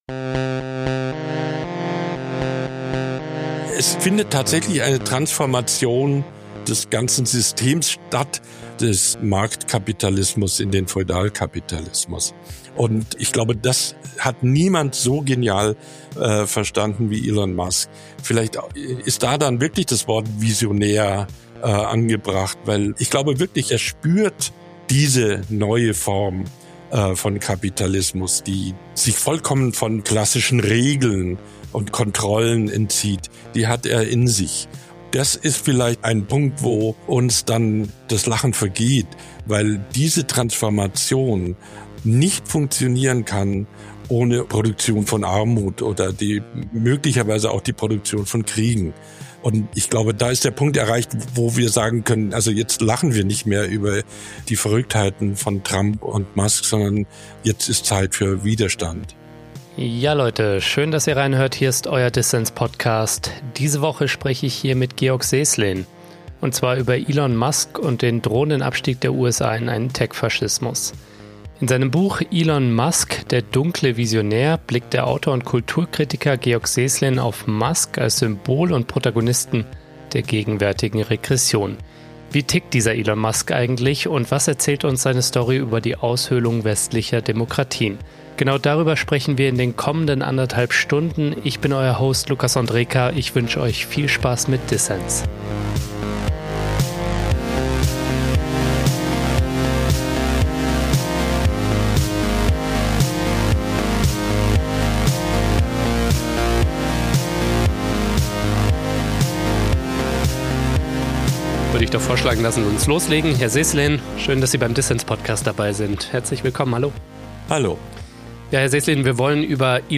Im Dissens Podcast spricht Georg Seeßlen über Elon Musk als Symbol und Speerspitze der gegenwärtigen Regression und darüber, was es braucht, um die Demokratie in den USA aus den Fängen der Musk-Trump-Bromance zu befreien.